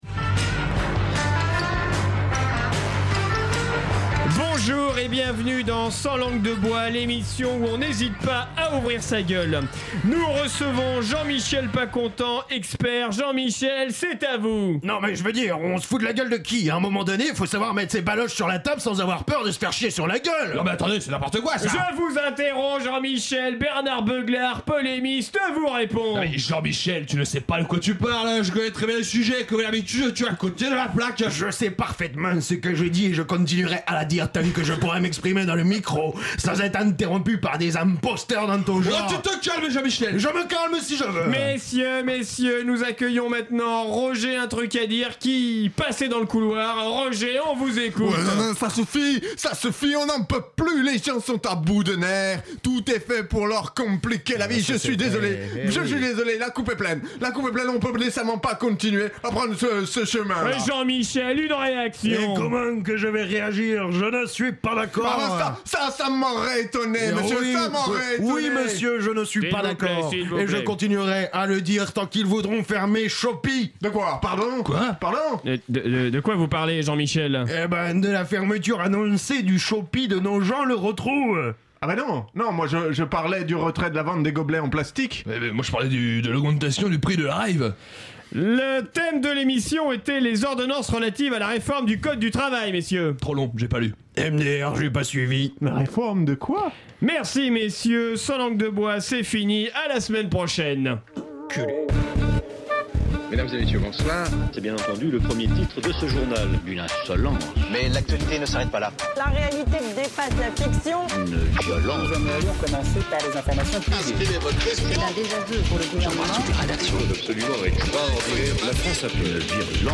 un live du collectif Flore sur la gauche